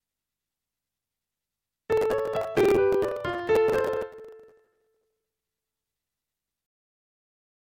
Distorted sound
I recently fitted a MAYA 44 eX pci soundcard. It plays music from My Music OK, but when I record in Reaper the recorded sound is very distorted as in attachment.